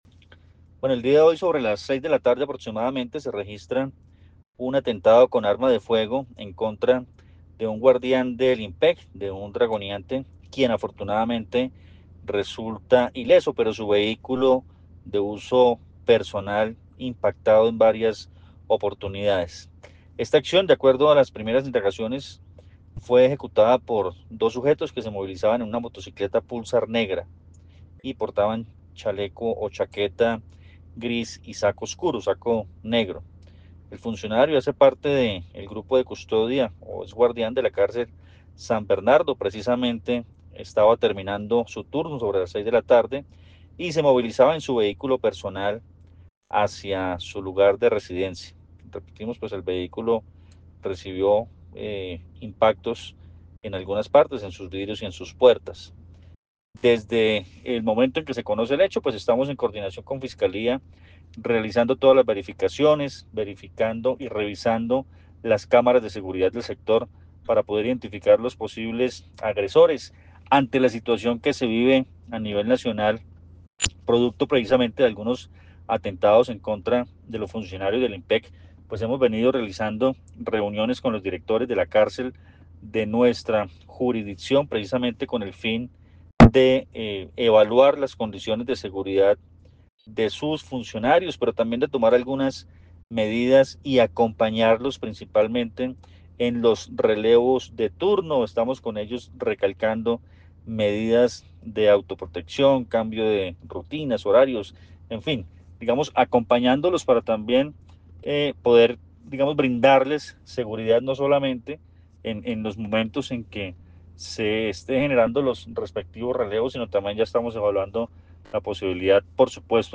Coronel Luis Fernando Atuesta sobre atentado